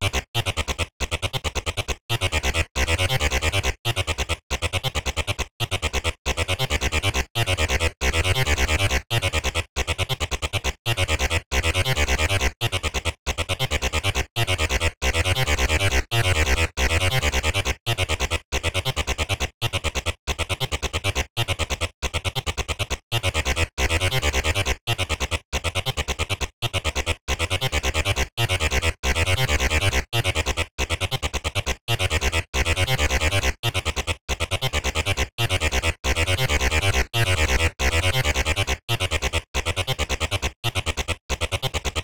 • acid pit techno bass sequence Cm - 137.wav
acid_pit_techno_bass_sequence_Cm_-_137_o5I.wav